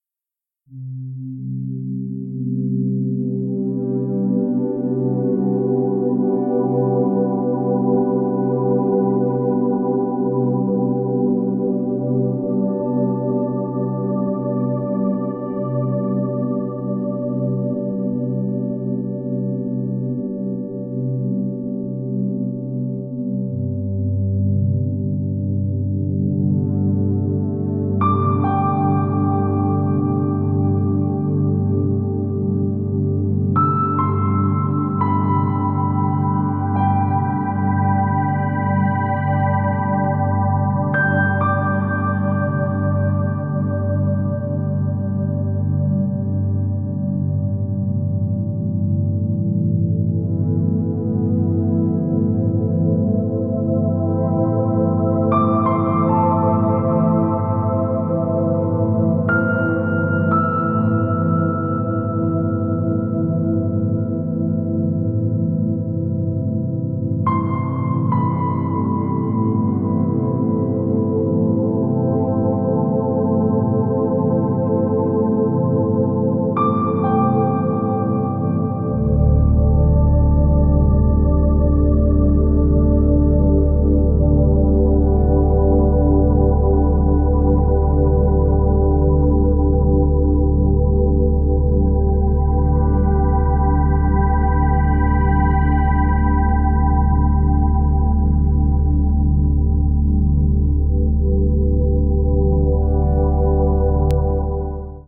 丁寧に音の重ねて深遠な世界を描いてます！